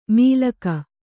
♪ mīlaka